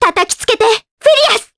Ophelia-Vox_Skill2_jp.wav